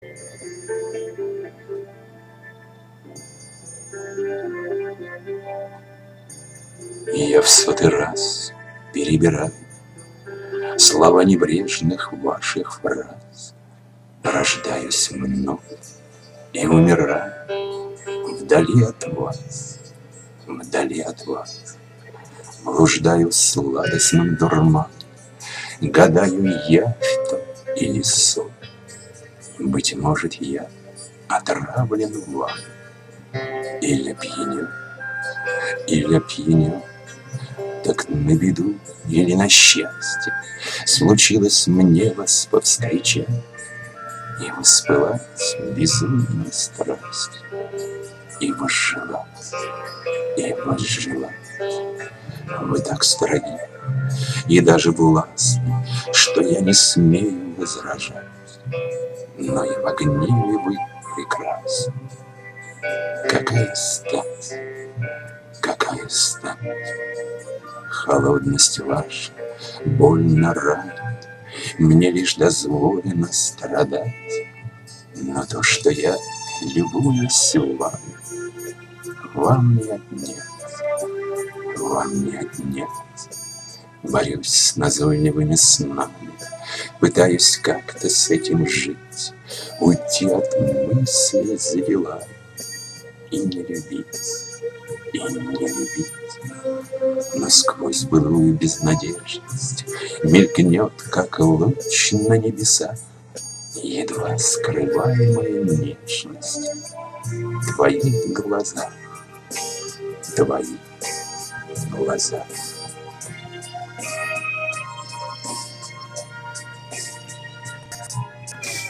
Доброй летней субботы всем завалинцам, чтобы немножко охладиться в этот жаркий день предлагаю немного аудиорифм, прошу прощения за качество это первые опытные записи.
К сожалению то примитивное устройство, которым я пользуюсь делает ямы и создается впечатление, что я проглатываю буквы, а то и слоги.